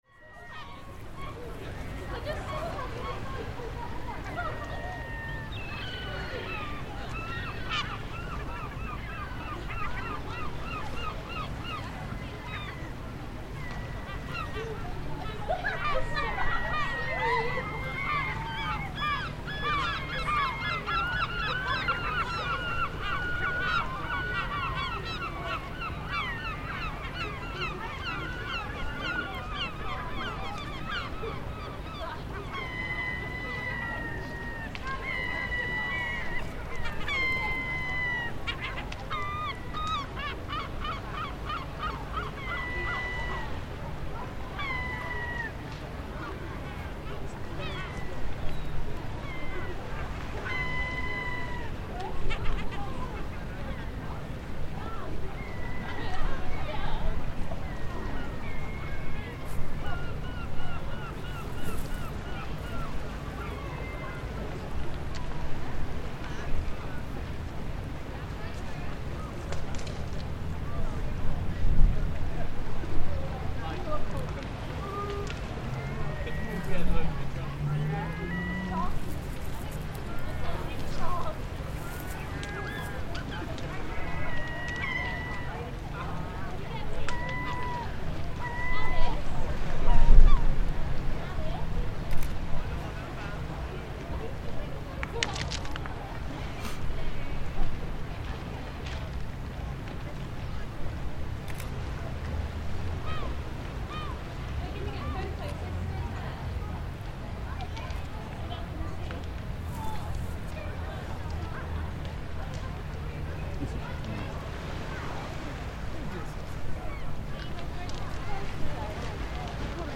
Gull argument at Stair Hole
At the Durdle Door area of outstanding beauty in Dorset, England, the Stair Hole cove is a secluded, reverb-drenched cove tucked away behind the cliffs. The gentle swish of waves blends with the sounds of holidaymakers escaping the 2020 Covid-19 lockdowns, and above that the sounds of gulls wheeling and arguing among themselves is the key feature in the soundscape.